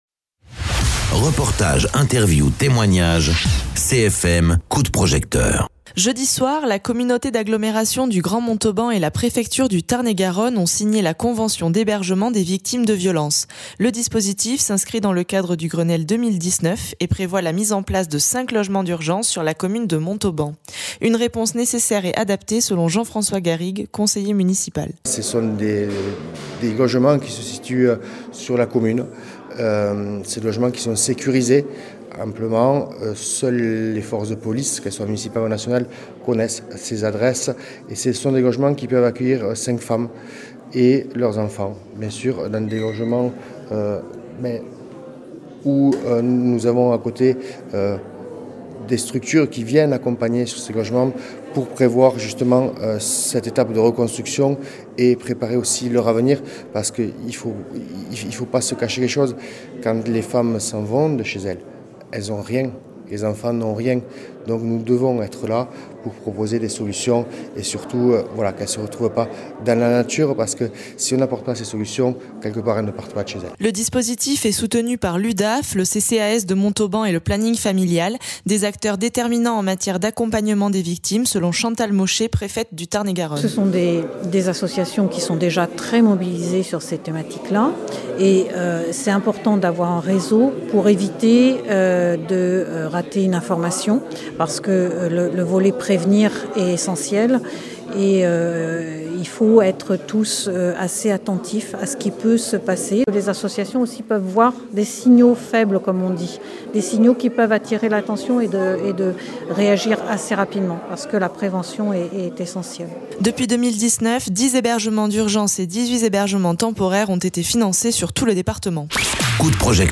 Interviews
Invité(s) : Jean-François Garrigues, conseiller municipal
Chantal Mauchet, préfète du Tarn-et-Garonne